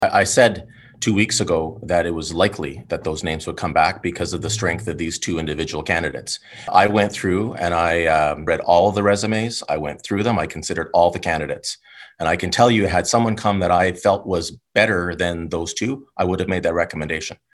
Mayor Panciuk defended his position during the meeting, once again stressing that these two candidates were the best for the job.